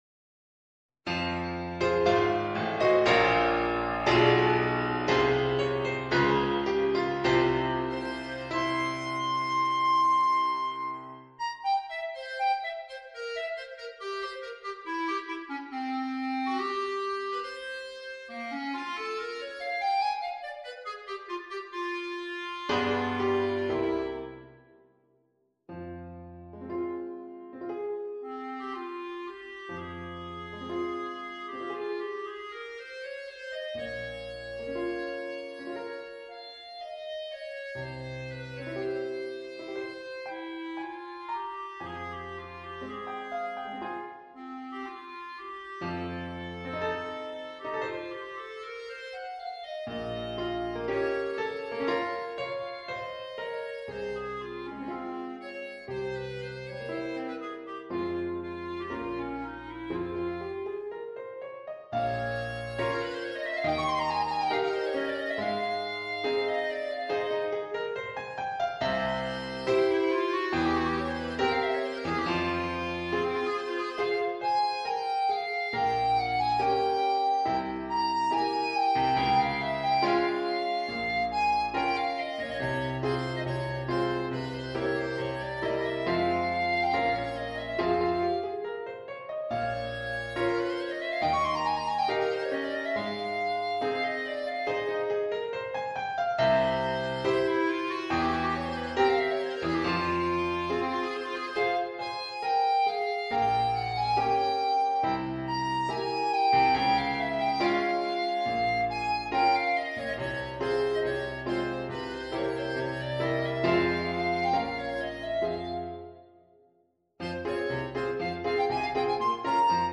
per clarinetto picc. e pianoforte